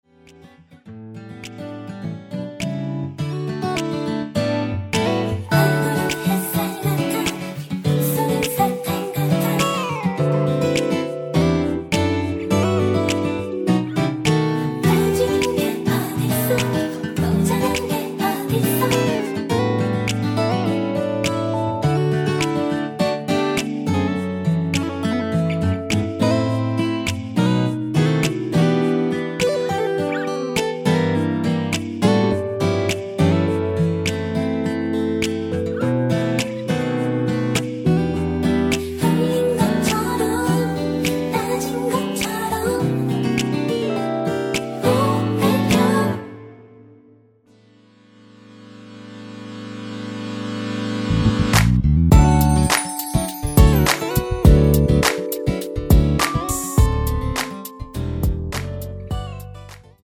코러스 포함된 MR 입니다.(미리듣기 참조)
앞부분30초, 뒷부분30초씩 편집해서 올려 드리고 있습니다.